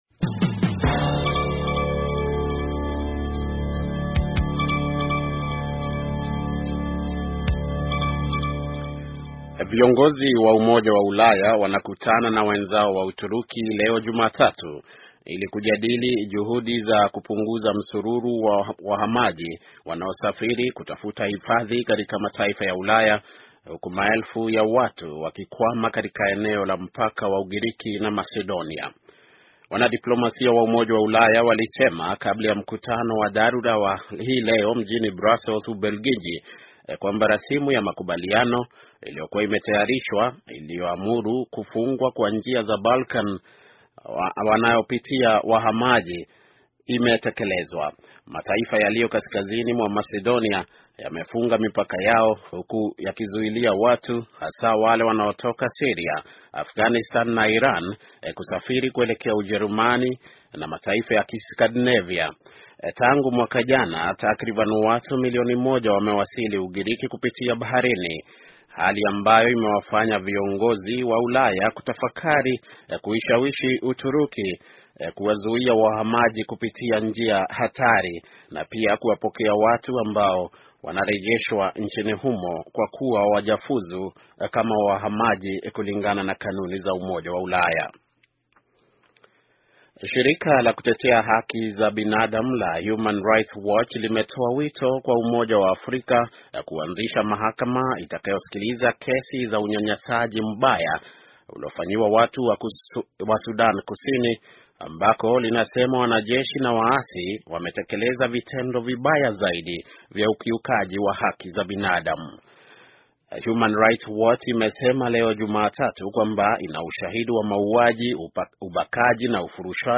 Taarifa ya habari - 7:08